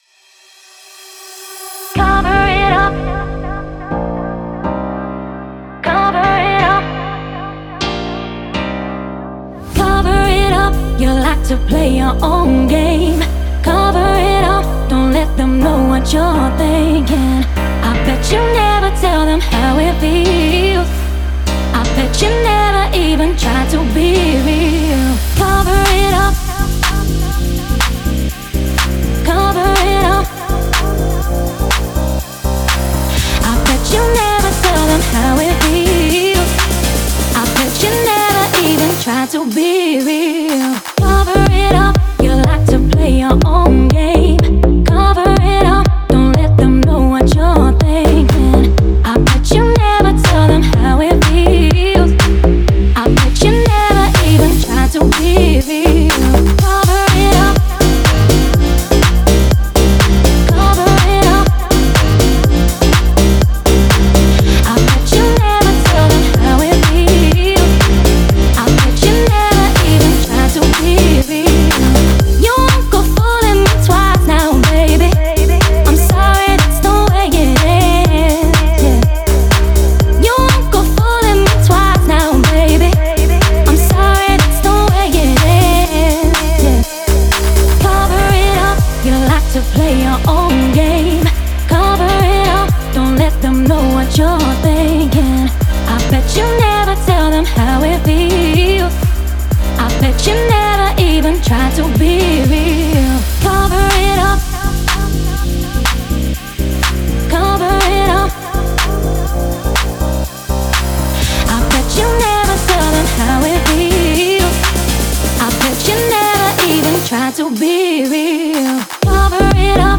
это захватывающая песня в жанре инди-фолк